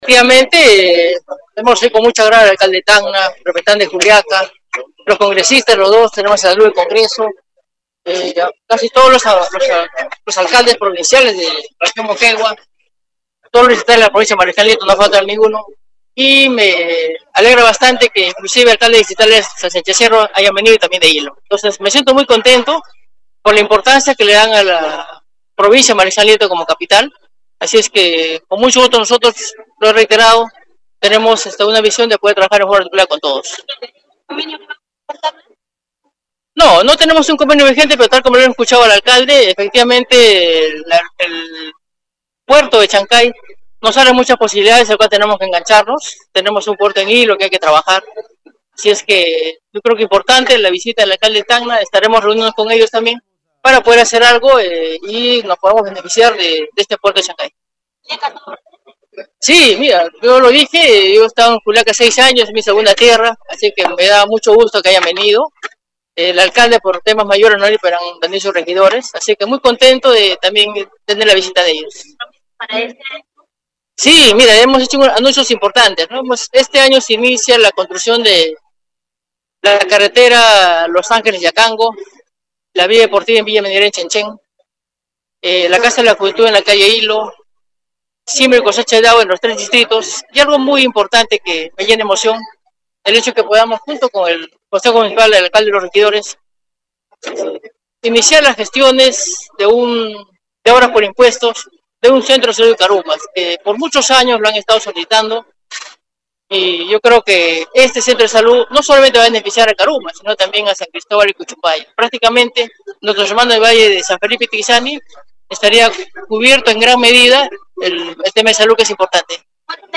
«Considero que los años que vienen son los mas importantes. Lo bueno es que tenemos expedientes terminados, ya no hay excusas para no llevar a cabo las obras que se anunciaron en campaña política«, expresó el burgomaestre a Radio Uno que realizó este lunes 25 cobertura especial al despliegue de aniversario.